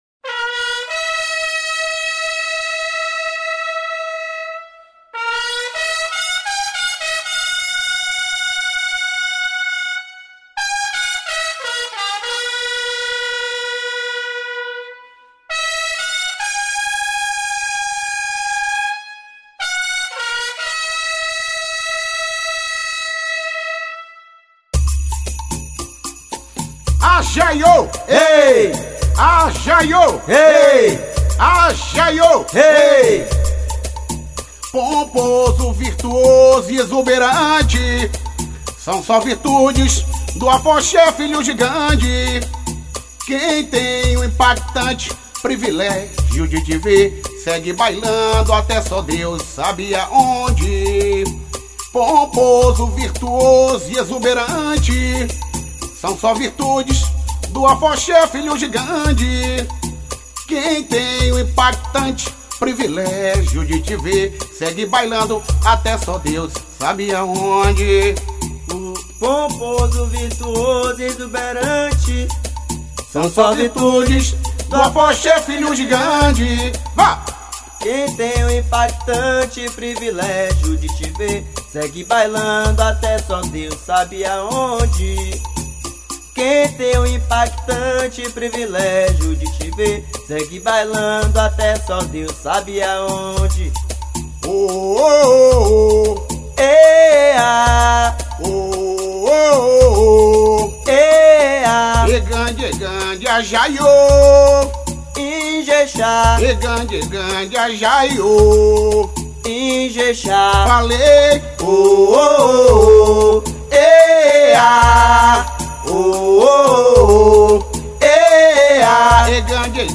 EstiloWorld Music